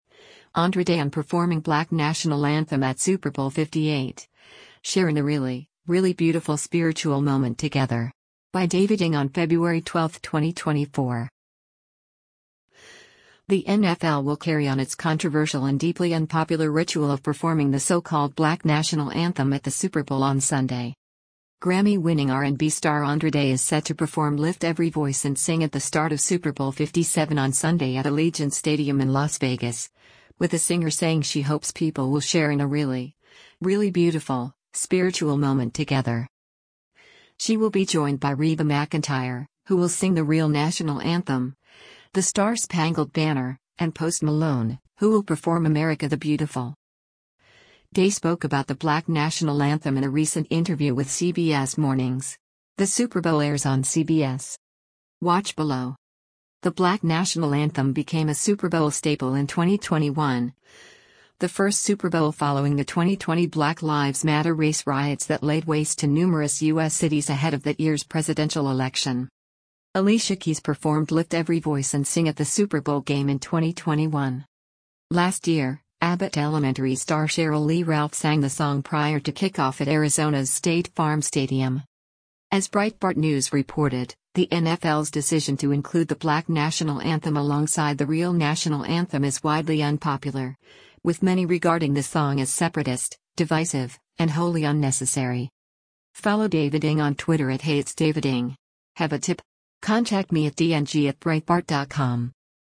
Day spoke about “the black national anthem” in a recent interview with CBS Mornings.